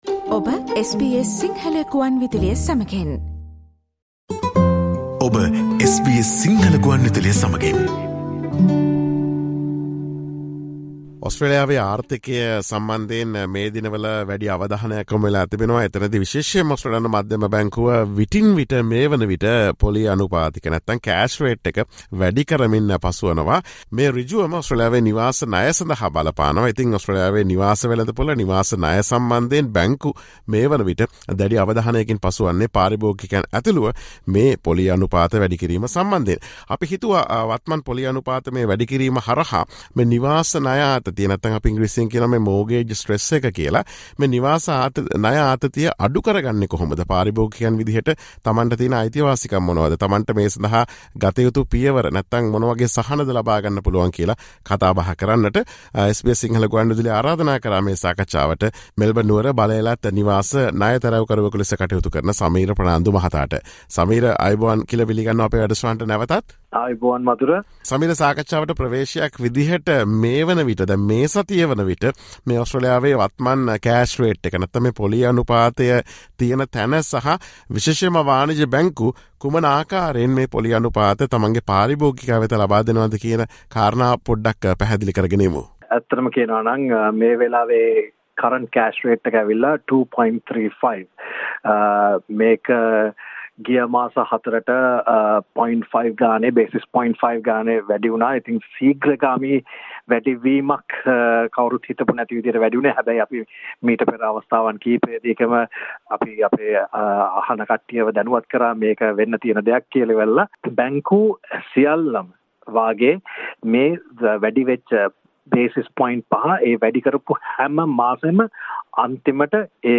Listen to SBS Sinhala Radio's discussion on ways home loan holders can reduce the mortgage stress caused by rapidly rising interest rates in Australia.